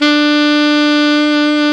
Index of /90_sSampleCDs/Roland L-CDX-03 Disk 1/SAX_Alto Tube/SAX_Alto mp Tube
SAX ALTOMP08.wav